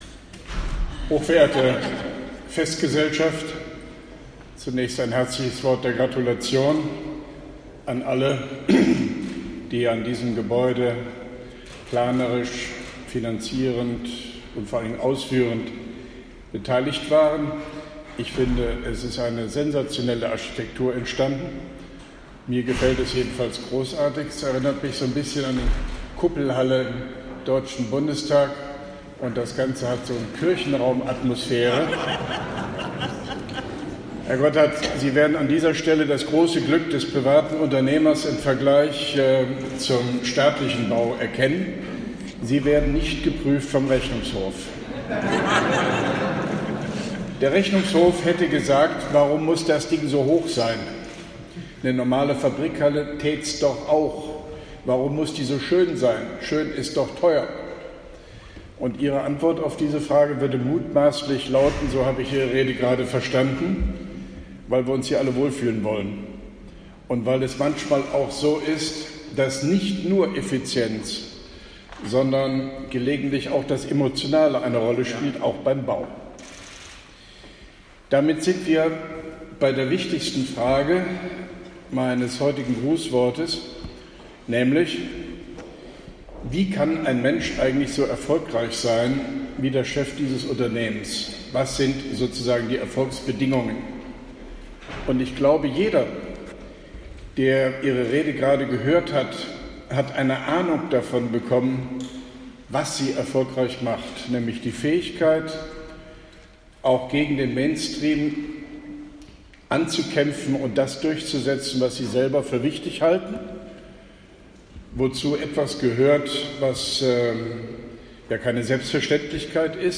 Grußwort von OB Hofmann-Göttig zur Einweihung der neuen Gebäude 8 der CompuGroup Medical SE, Koblenz 15.05.2017